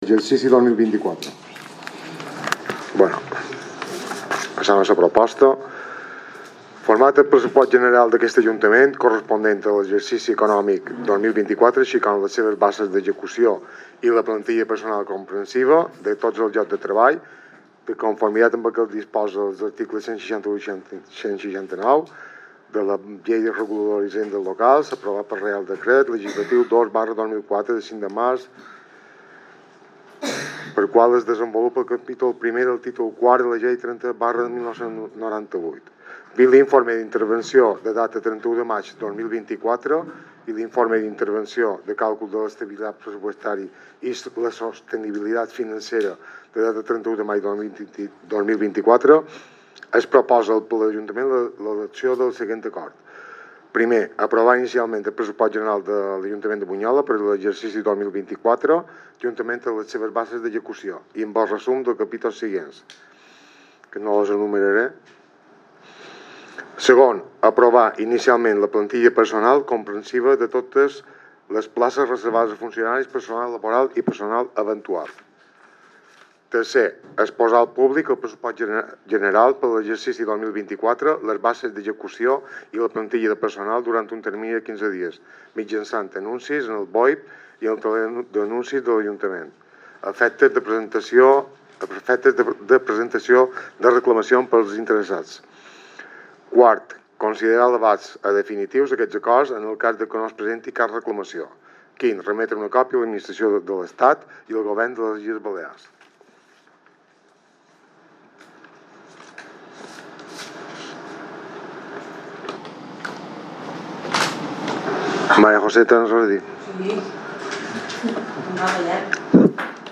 Sessió del Ple Extraordinari i Urgent de l'Ajuntament de Bunyola que es celebrarà el proper dijous dia 18 de juny a les 19:30 hores, a la Sala Plenaris Ajuntament.